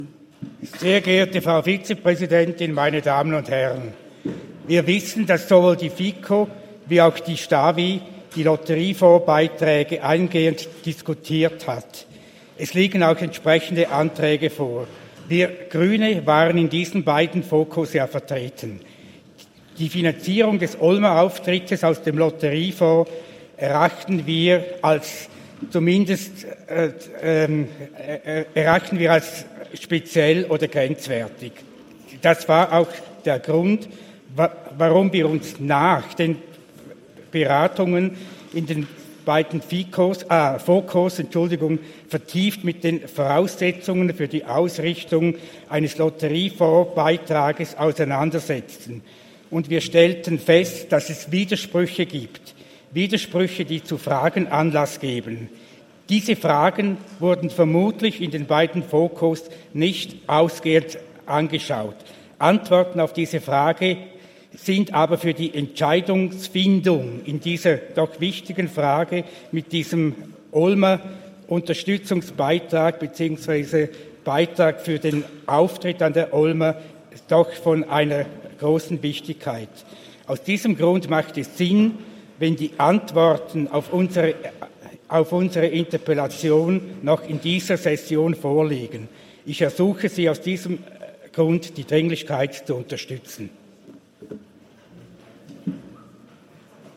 13.6.2023Wortmeldung
Session des Kantonsrates vom 12. bis 14. Juni 2023, Sommersession